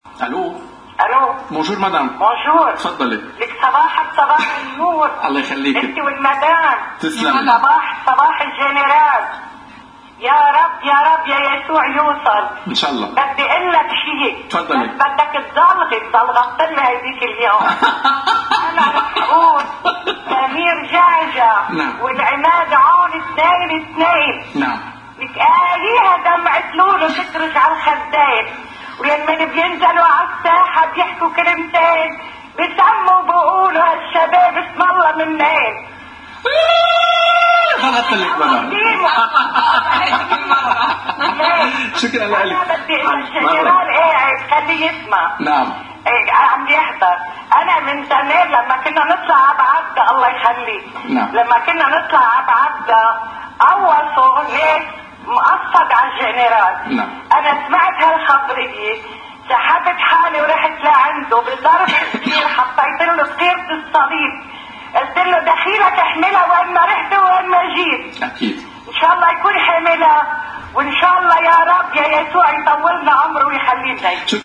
بالصوت شهادة سيدة.. بس قوصّو عَ الجنرال عطيتو ظرف فيه “دخيرة الصليب” لتحميه
متصلة تُهدي موالاً لعون وجعجع..
اتصلت إحدى السيدات ضمن برنامج “جريدة اليوم” على شاشة الـ”otv“، ومما قالته: